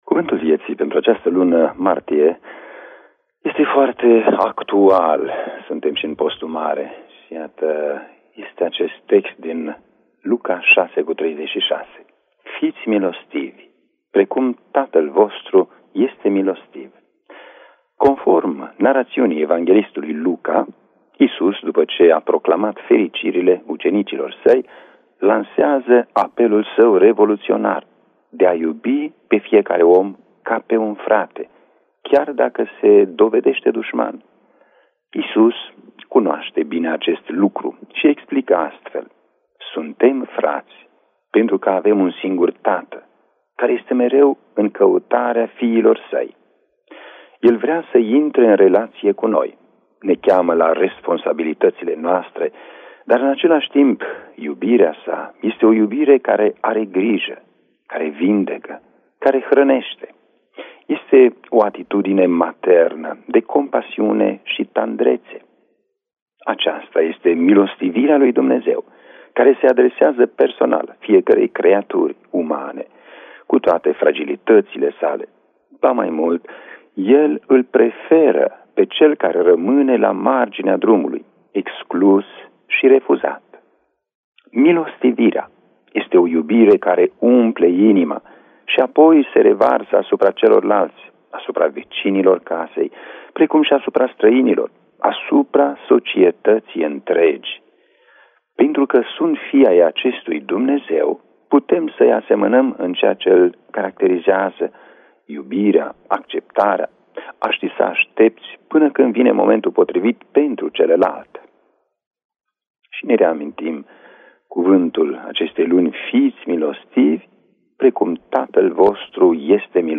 în interviul pentru Radio Maria